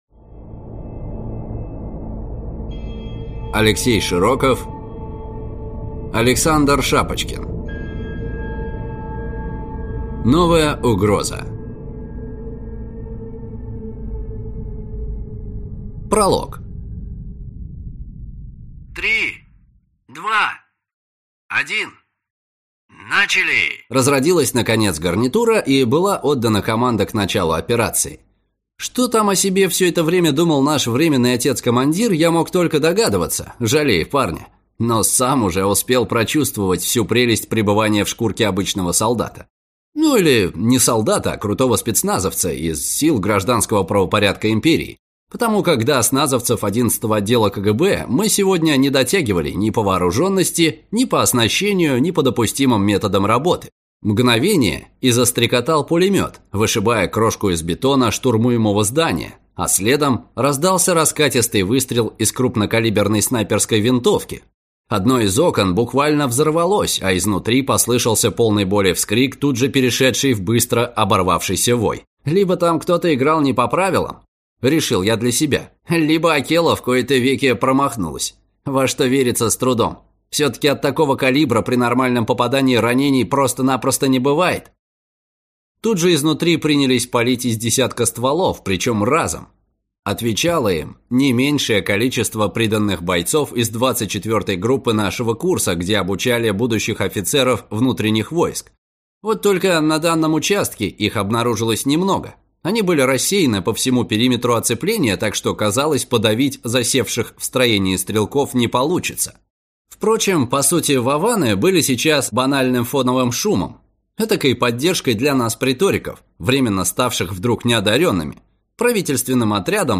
Аудиокнига Новая угроза | Библиотека аудиокниг